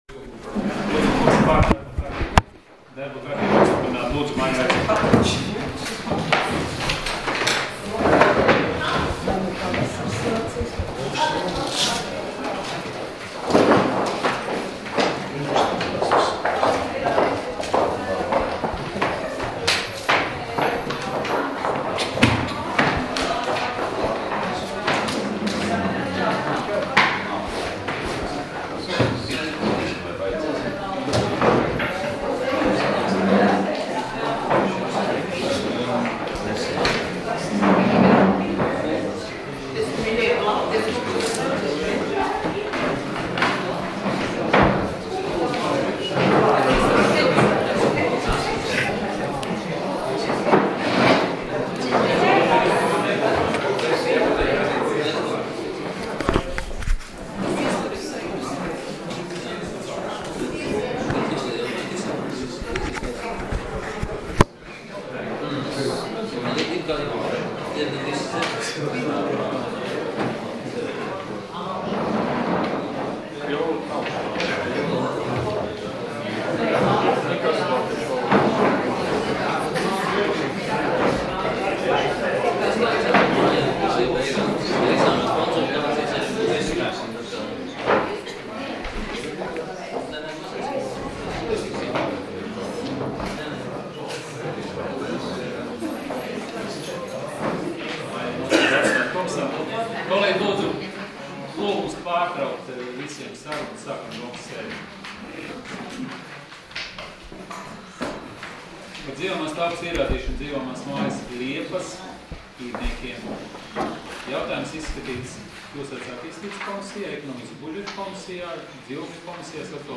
Domes sēdes 10.03.2017. audioieraksts